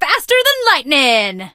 max_kill_vo_04.ogg